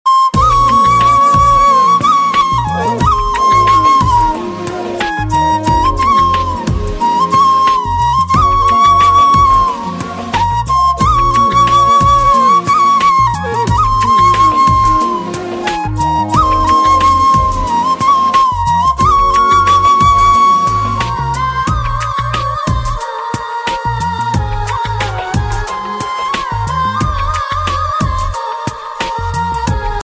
Bollywood - Hindi